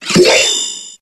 Grito de Doublade.ogg
Grito_de_Doublade.ogg.mp3